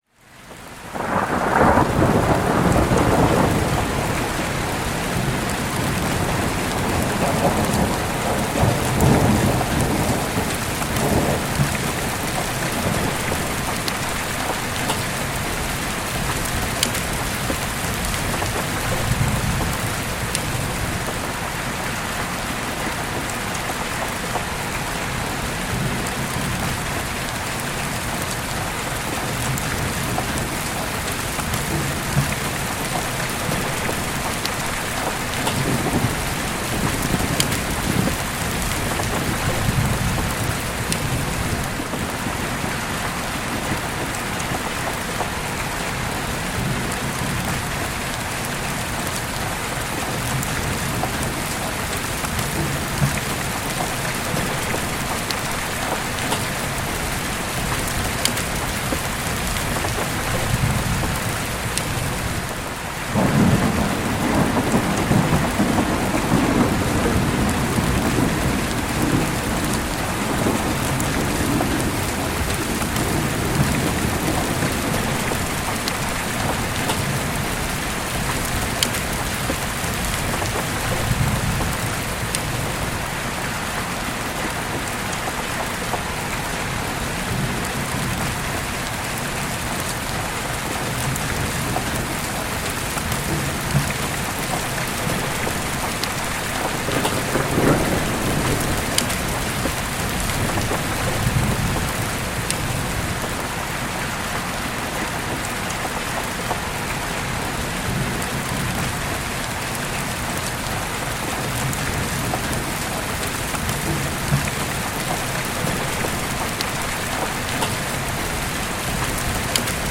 Tormenta Suave para un Sueño Profundo y Energía Renovada
En Descanso Con Lluvia, todos los anuncios viven al inicio de cada episodio porque entendemos lo importante que es mantener intacto ese espacio en el que tu mente empieza a relajarse.
Sonido de Lluvia, Lluvia Relajante, Lluvia Suave, Lluvia Nocturna